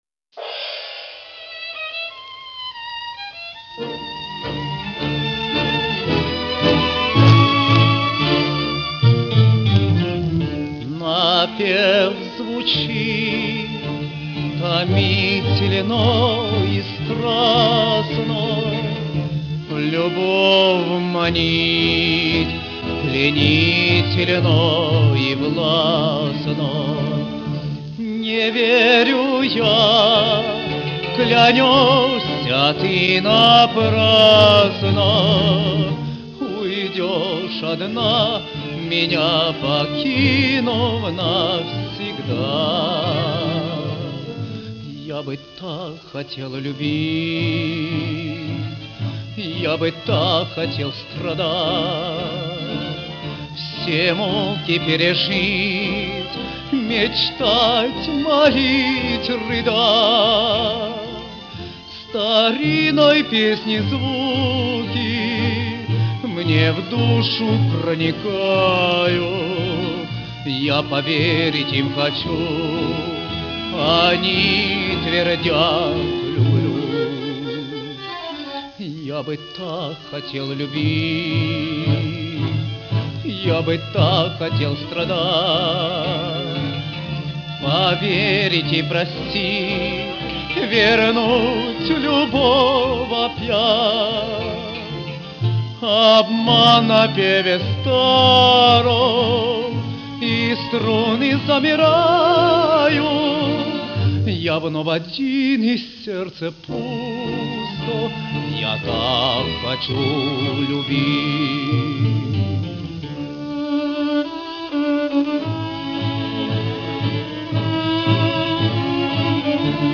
” Танго